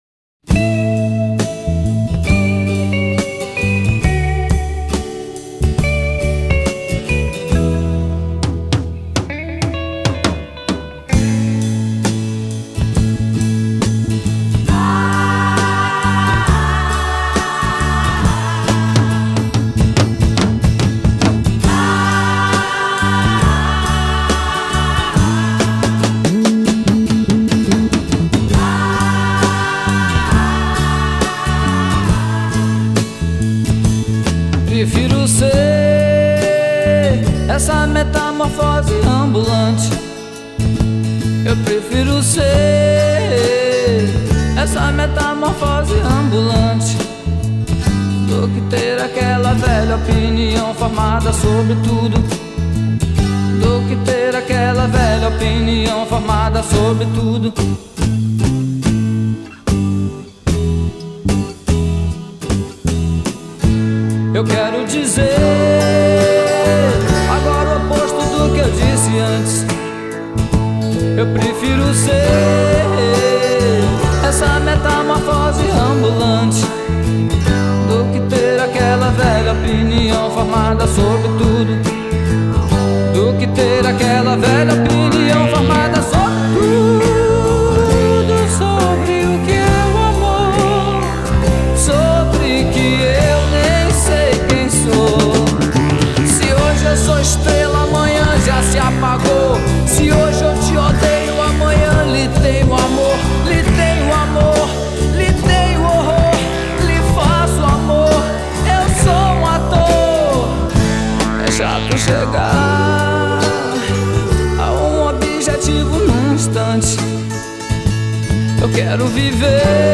2025-02-13 16:22:34 Gênero: Bossa Nova Views